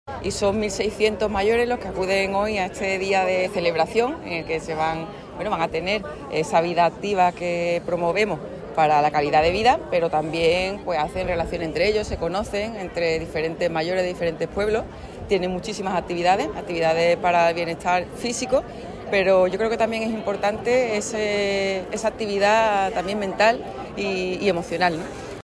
Almudena Martínez abre la jornada celebrada en El Puerto
Mayores-activos_presidenta.mp3